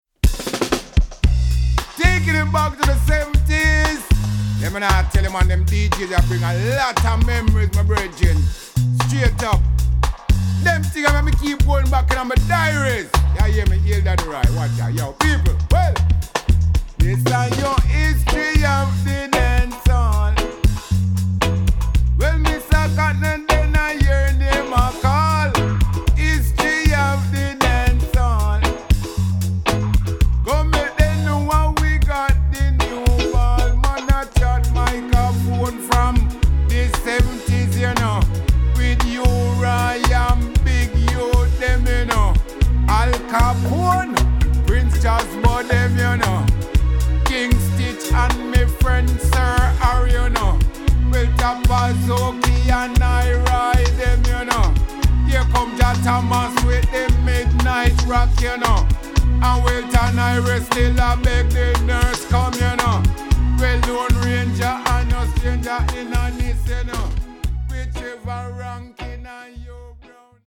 Vocals recorded in Paris.